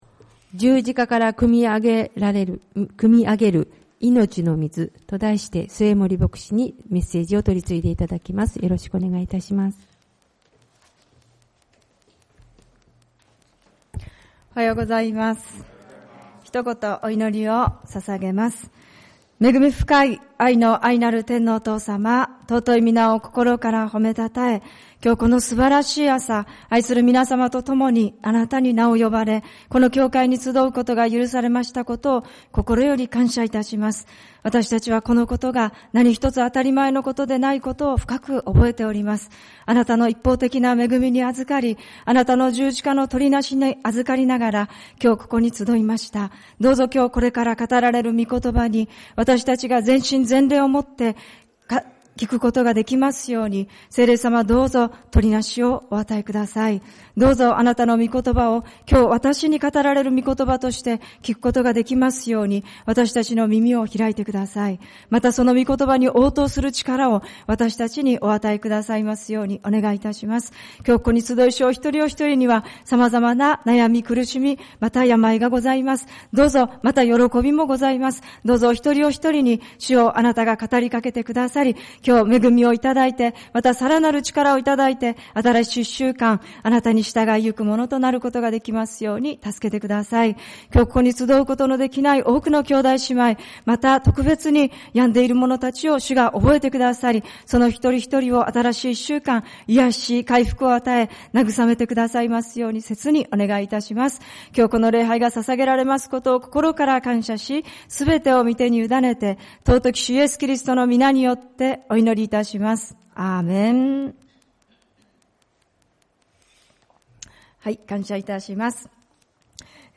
主日礼拝 「十字架から汲みあげる命の水」 ルカによる福音書22:39-46